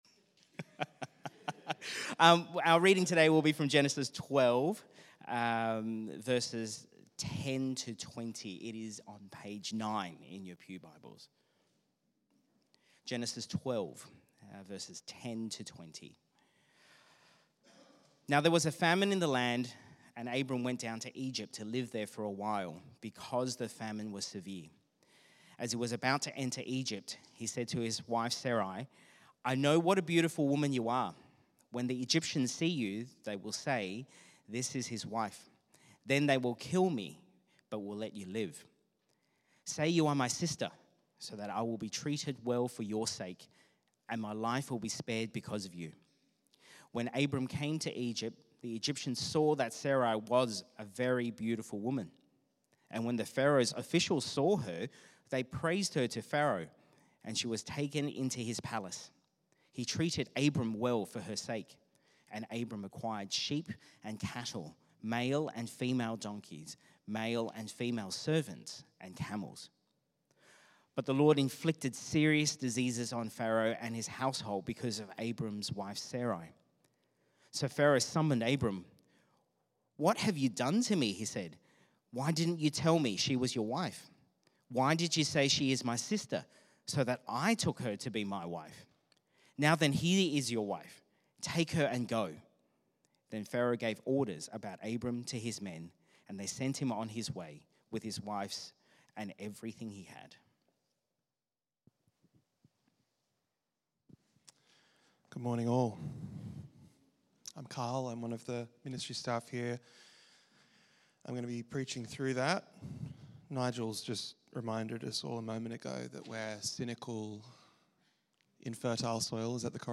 Sermon on Abraham’s fear and failure versus God’s unwavering faithfulness, pointing to Jesus’ sacrificial love and our call to trust and share our faith.